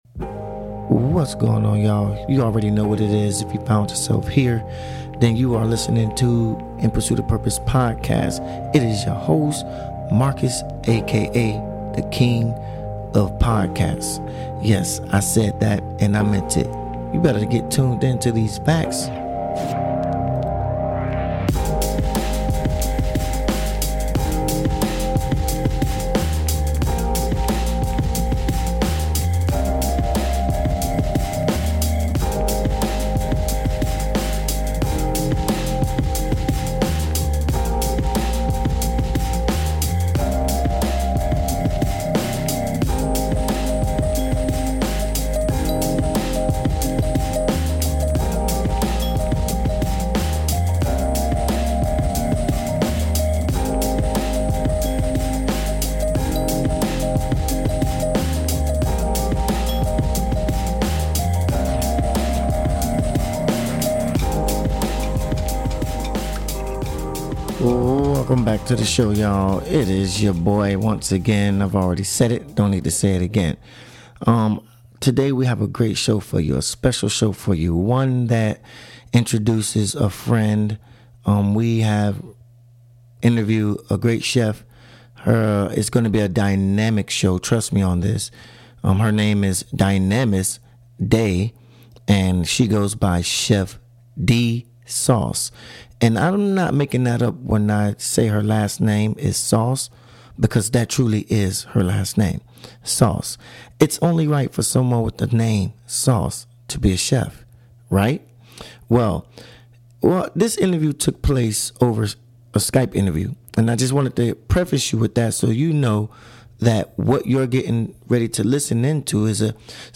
We outdid ourselves with this dynamic interview